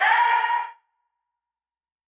10 Bands Vox.wav